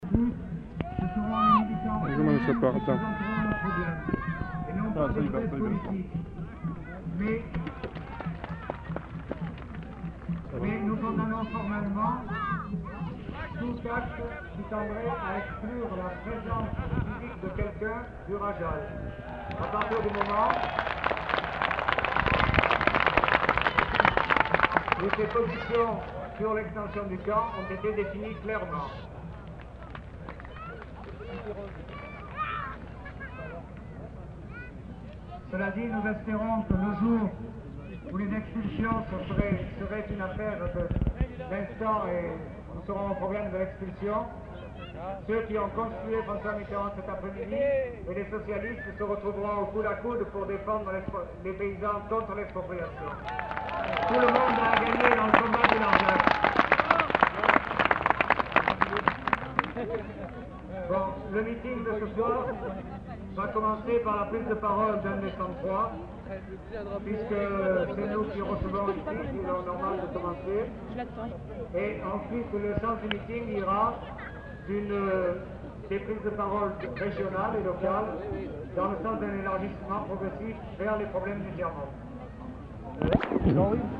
Extrait d'un discours sur l'expropriation des paysans du Larzac
Lieu : [sans lieu] ; Aveyron
Genre : parole